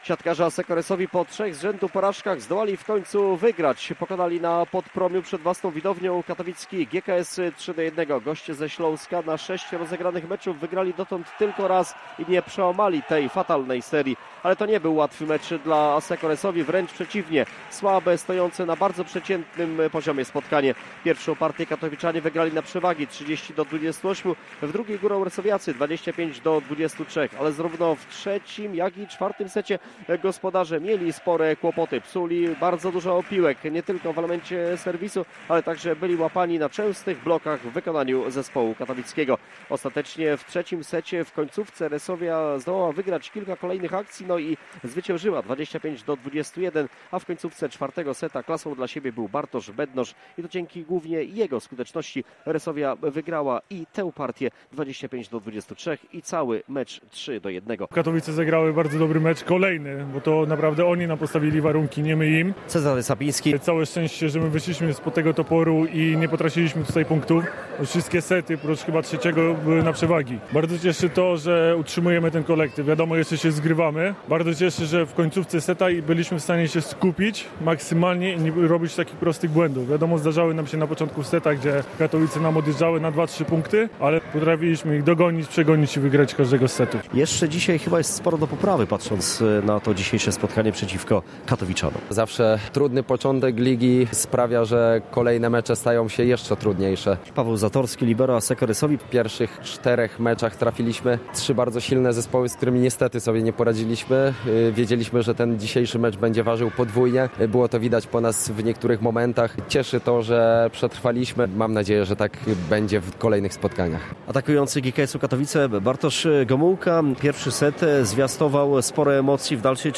Pomeczowa relacja